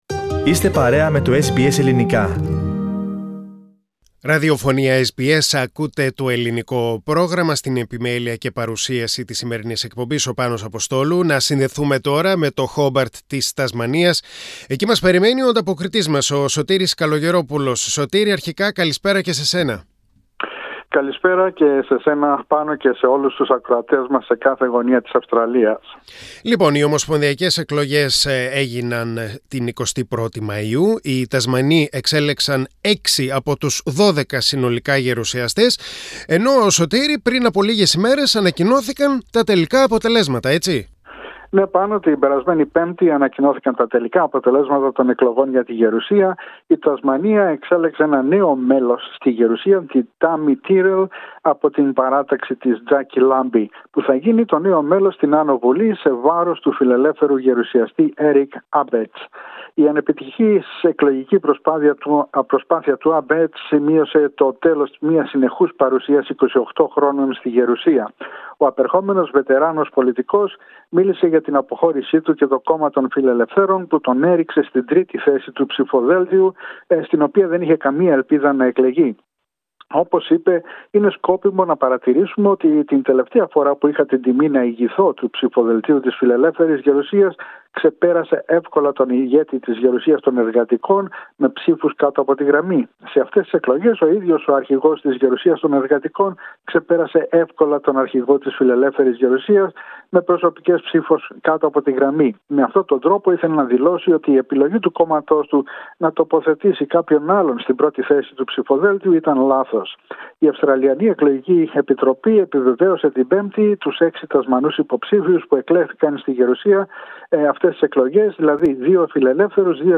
Η εβδομαδιαία ανταπόκριση από το Χόμπαρτ της Τασμανίας.